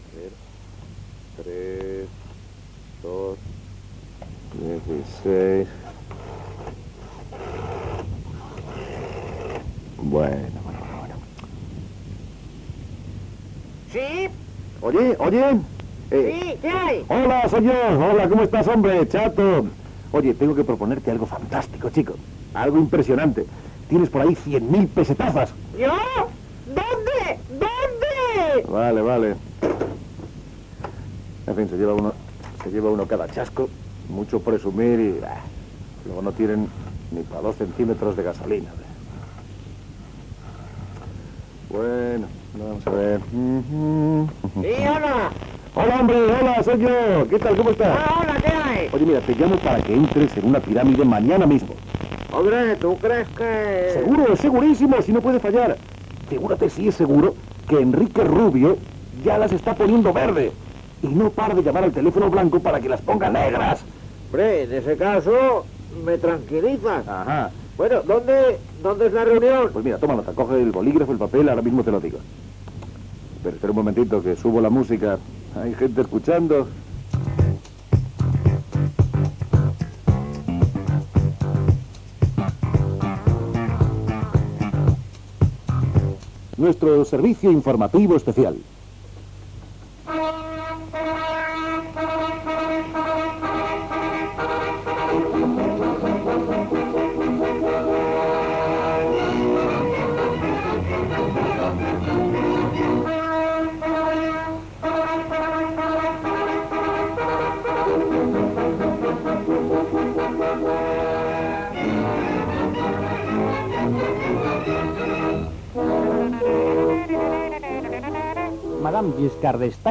Trucades telefòniques invitant a participar en un negoci piramidal
Entreteniment